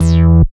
69.08 BASS.wav